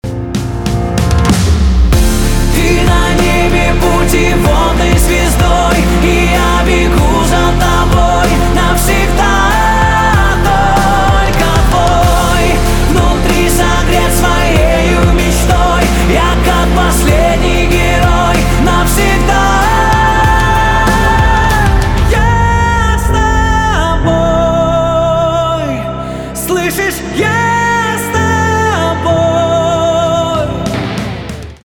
поп
чувственные , гитара , барабаны , романтические